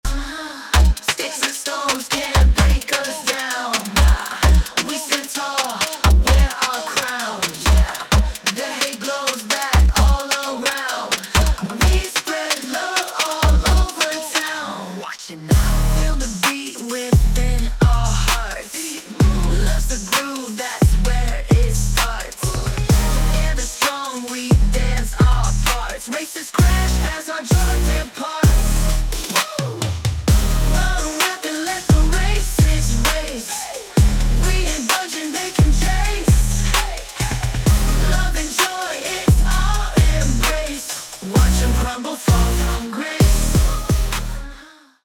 Short version of the song, full version after purchase.
An incredible Dance/Pop song, creative and inspiring.